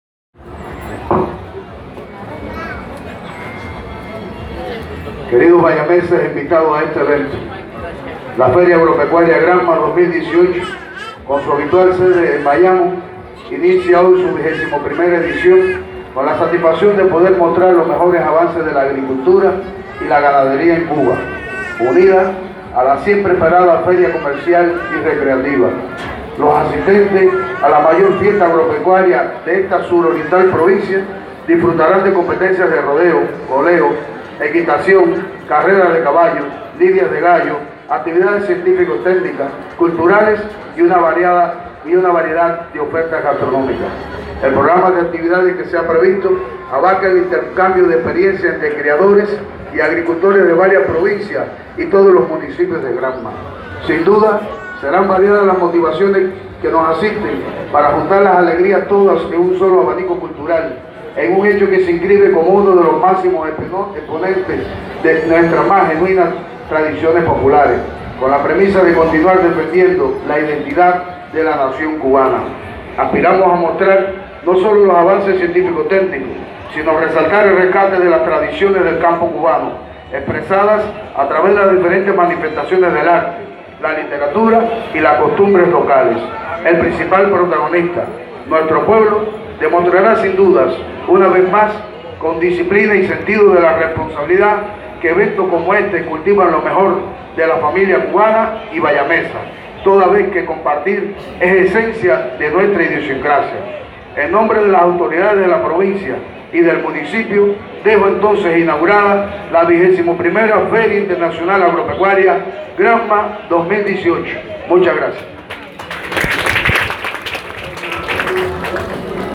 Samuel-Calzada-Deyudé-presidente-de-la-Asamblea-municipal-del-Poder-Popular-en-Bayamo.mp3